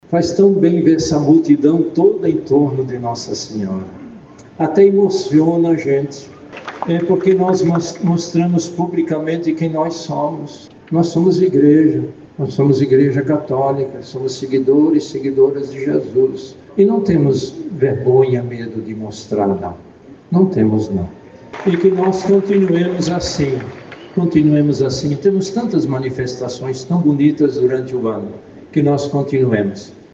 Durante a celebração, o arcebispo de Manaus, cardeal Leonardo Steiner, destacou a figura humilde e fiel de Maria, escolhida entre todas as mulheres para gerar aquele que trouxe a salvação à humanidade.